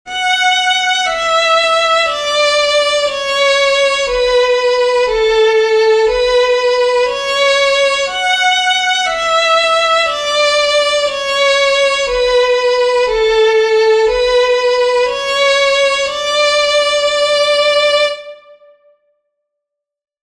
This is a three-voice canon, Pachelbel’s Canon in D major (with F# and C#). The score includes three different instruments (violin, recorder, guitar) so that the three voices can be distinguished.
First voice.
canon_a_tres_voces_voz1.mp3